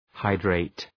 Προφορά
{‘haıdreıt}